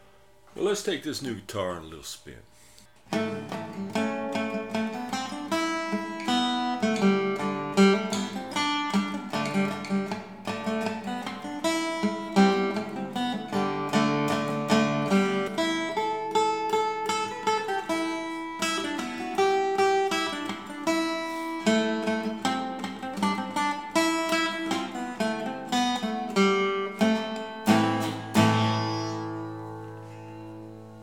What My New Homemade Guitar Sounds Like
A very folksy sound.
audio-cold-frostymorning-with-maple-guita-reverb.mp3